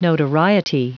Prononciation du mot notoriety en anglais (fichier audio)